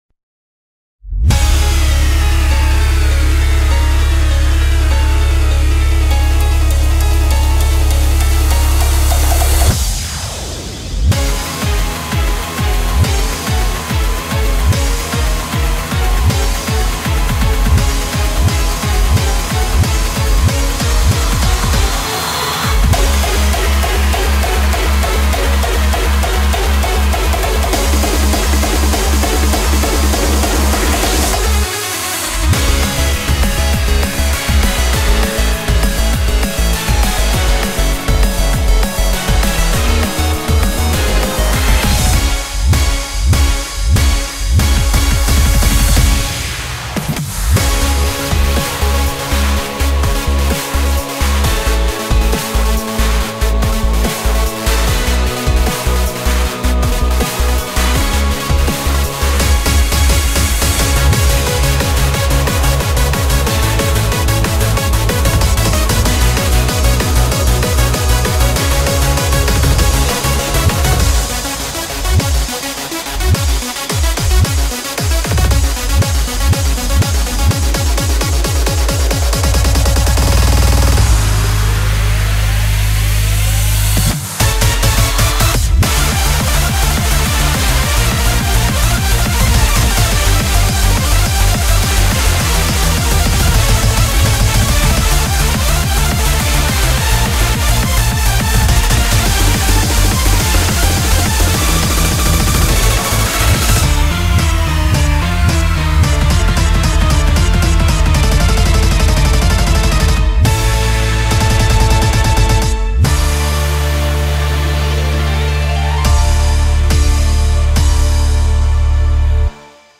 BPM120-420
Audio QualityCut From Video